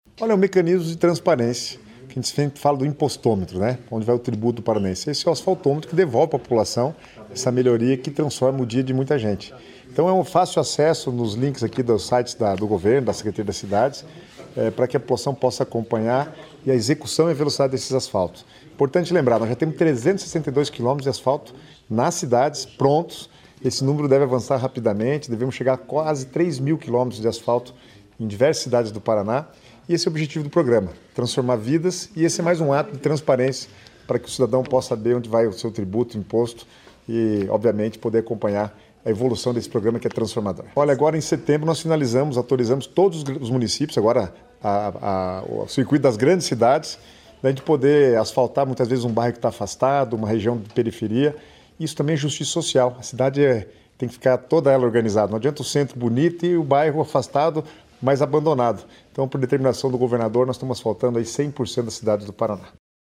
Sonora do secretário das Cidades, Guto Silva, sobre o Asfaltômetro